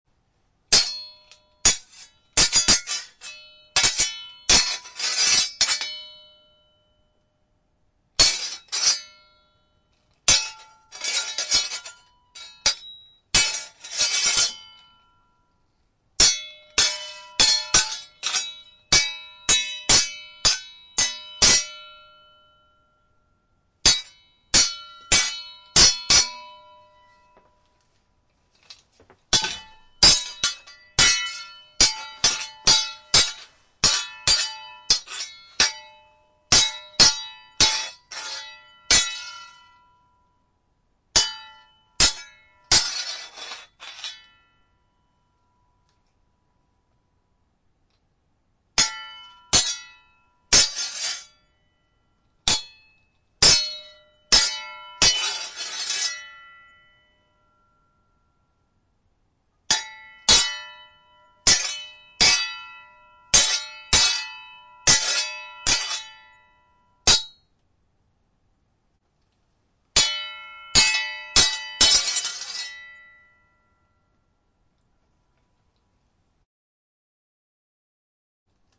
Download Sword Fight sound effect for free.
Sword Fight
Anime Sword Fight